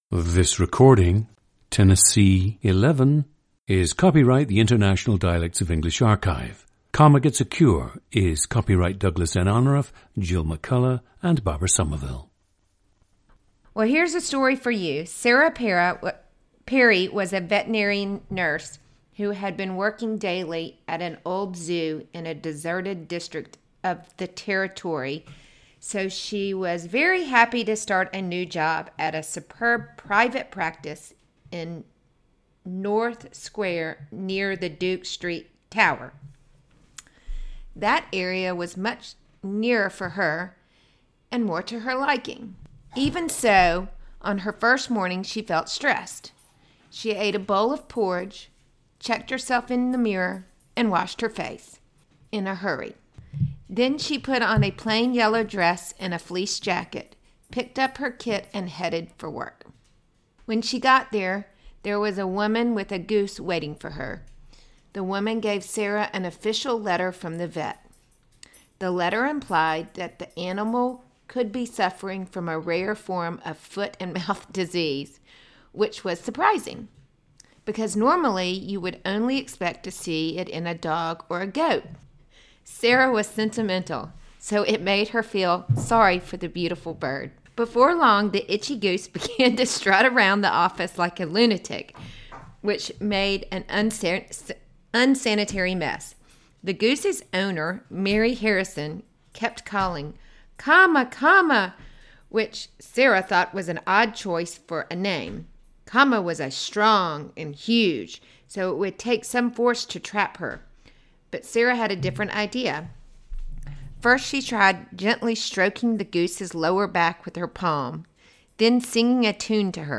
Listen to Tennessee 11, a 50-year-old woman from Memphis, Tennessee, and South Carolina, United States.
GENDER: female
• Recordings of accent/dialect speakers from the region you select.
The recordings average four minutes in length and feature both the reading of one of two standard passages, and some unscripted speech.